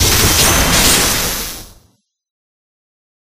Thunder13.ogg